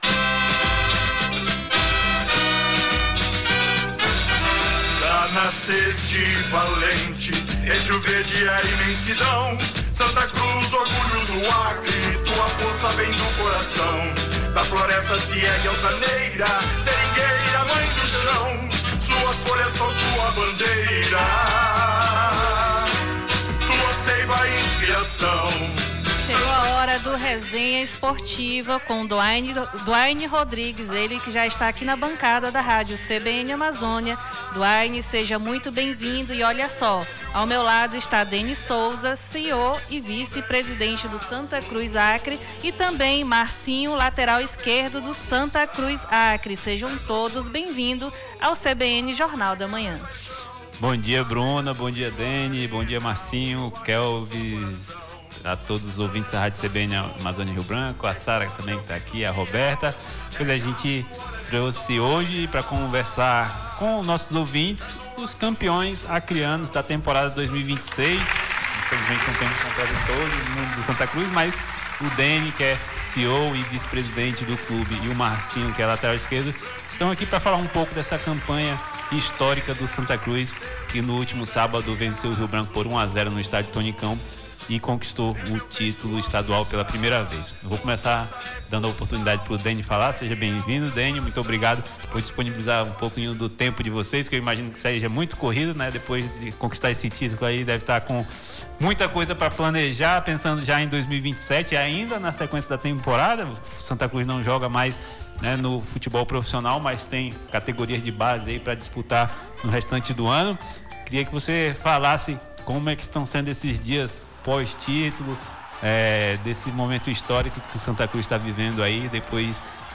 recebemos no estúdio da rádio CBN Amazônia